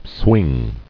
[swing]